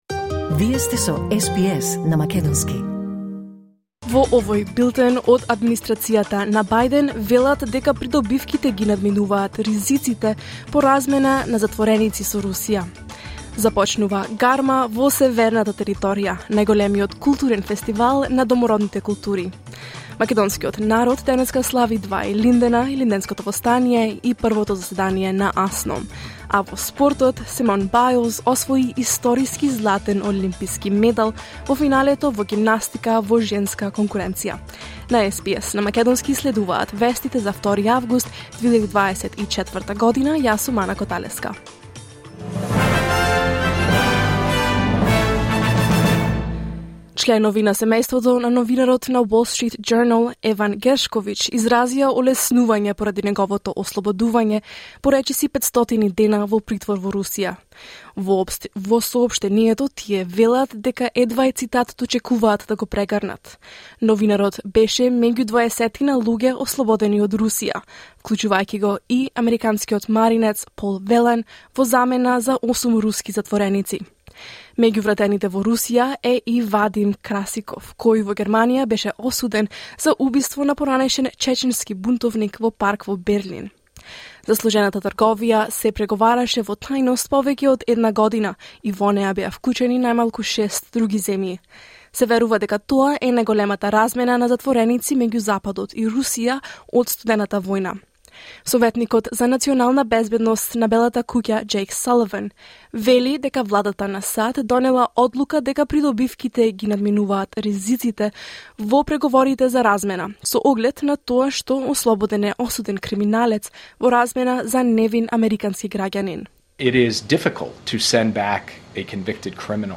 SBS News in Macedonian 2 August 2024